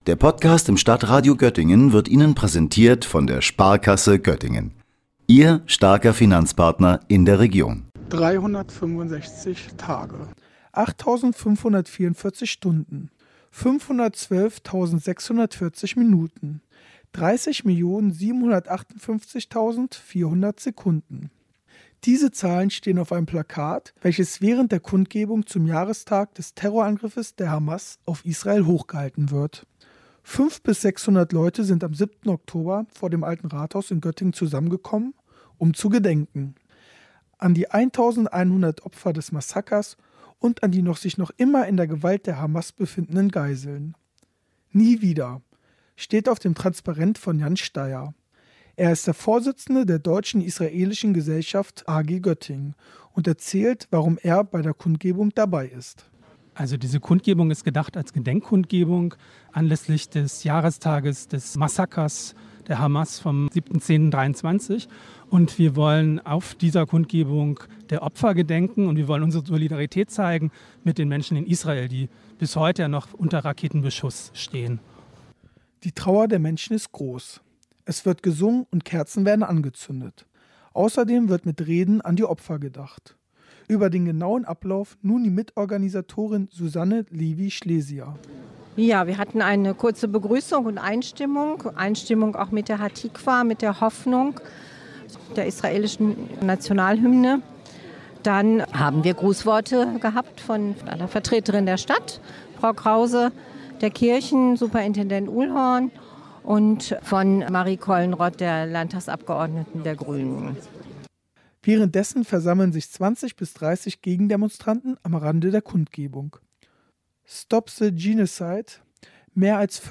Beiträge > Gedenken an die Opfer des Massakers vom 7. Oktober - Kundgebung in Göttingen - StadtRadio Göttingen
Zum Jahrestag des Angriffs der Hamas auf Israel kamen etwa 500-600 Menschen zu einer Kundgebung vor dem alten Rathaus zusammen. Thema waren die Opfer des Massakers der Hamas, aber auch die Geiseln,die sich immer noch in ihrer Gewalt befindend.